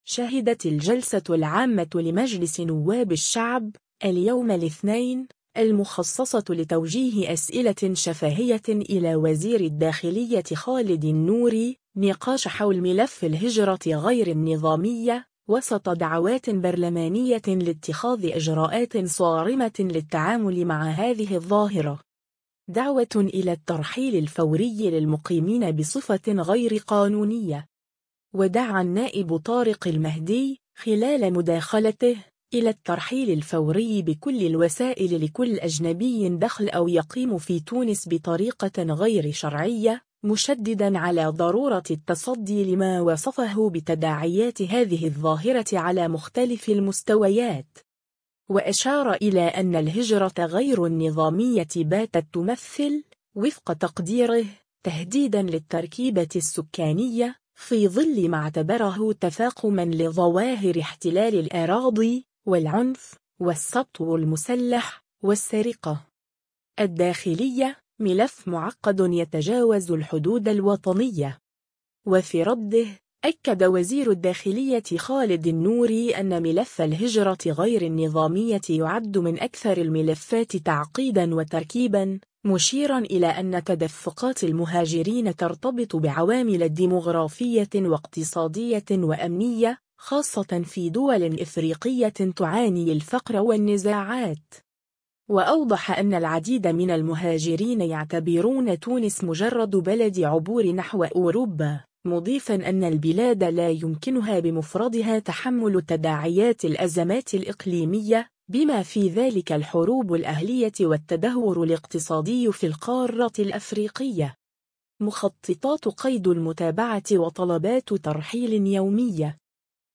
شهدت الجلسة العامة لمجلس نواب الشعب، اليوم الاثنين، المخصصة لتوجيه أسئلة شفاهية إلى وزير الداخلية خالد النوري، نقاشًا حول ملف الهجرة غير النظامية، وسط دعوات برلمانية لاتخاذ إجراءات صارمة للتعامل مع هذه الظاهرة.